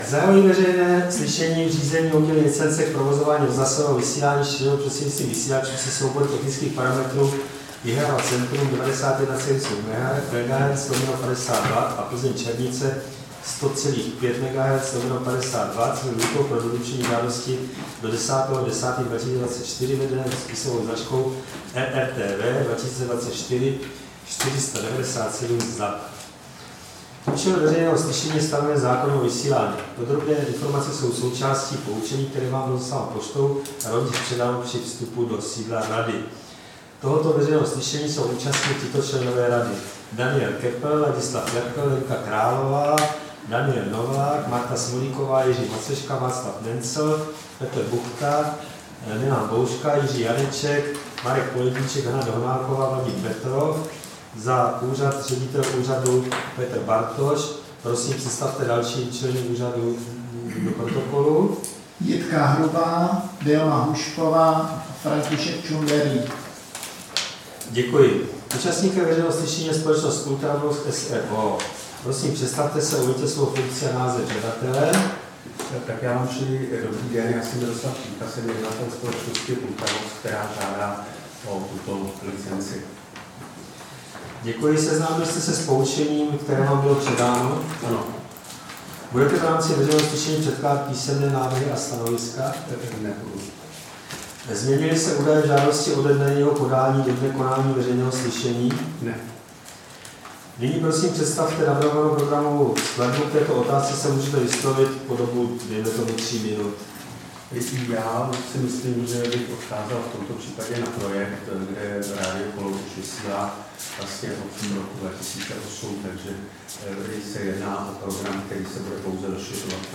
Veřejné slyšení v řízení o udělení licence k provozování rozhlasového vysílání šířeného prostřednictvím vysílačů se soubory technických parametrů Jihlava-centrum 91,7 MHz/50 W a Plzeň-Černice 100,5 MHz/50 W
Místem konání veřejného slyšení je sídlo Rady pro rozhlasové a televizní vysílání, Škrétova 44/6, 120 00 Praha 2.